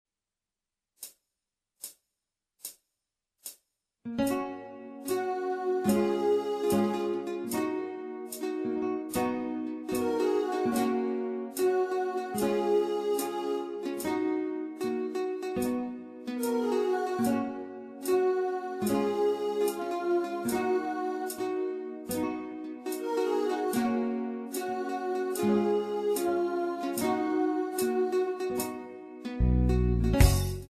Tempo: 74 BPM.
MP3 with melody DEMO 30s (0.5 MB)zdarma